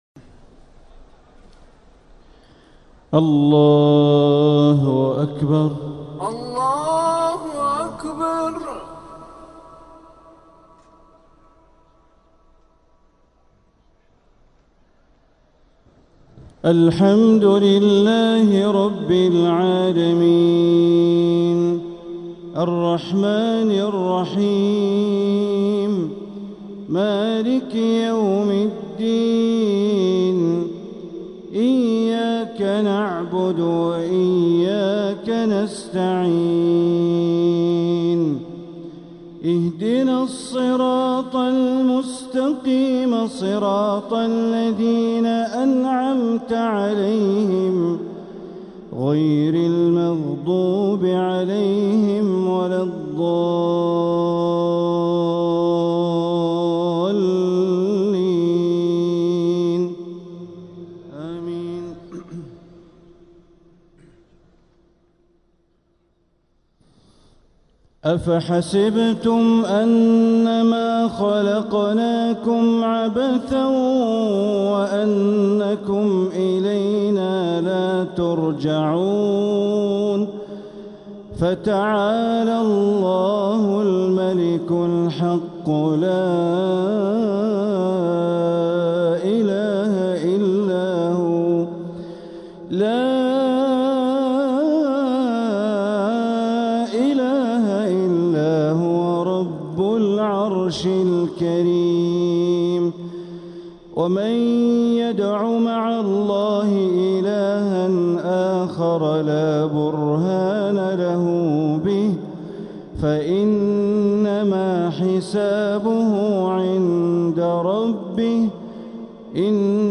تلاوة لخواتيم سورتي المؤمنون 115-118 و القيامة 36-40 | مغرب الأحد 5-9-1447هـ > 1447هـ > الفروض - تلاوات بندر بليلة